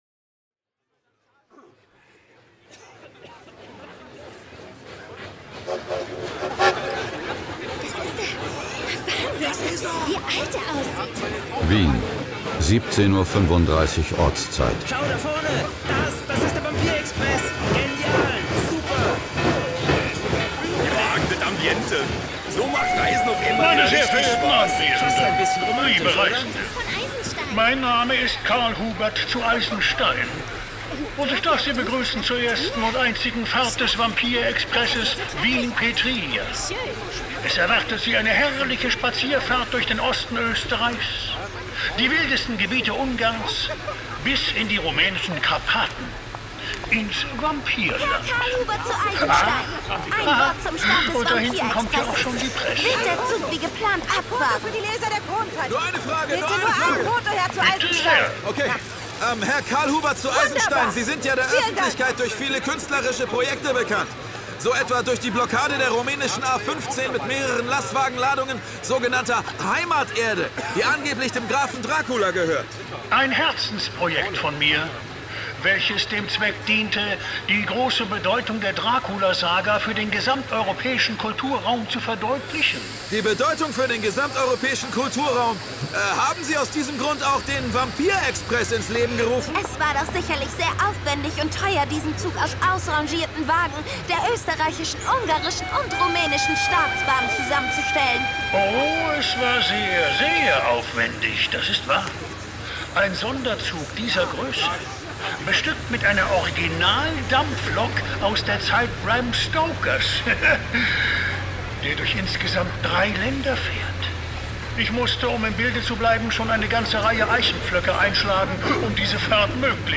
Jason Dark (Autor) Dietmar Wunder (Sprecher) Audio-CD 2020 | 1.